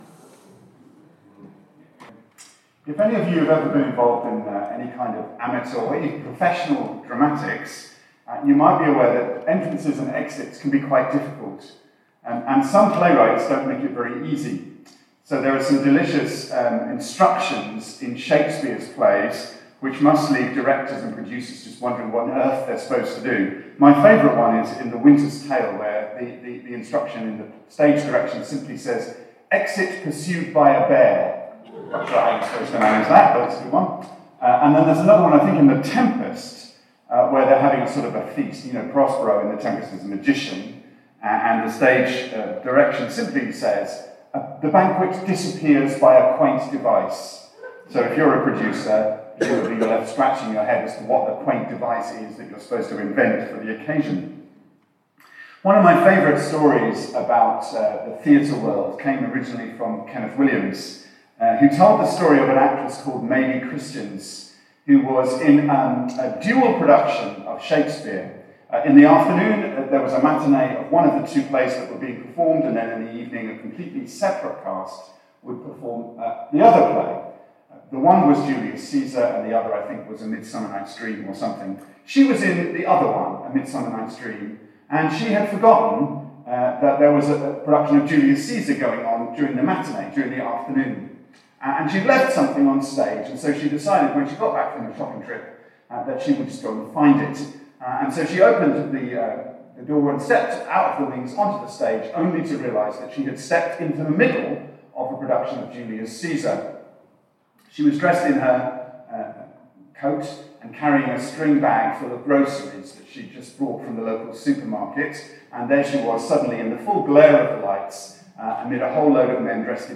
SERMONS - Southgate Methodist Church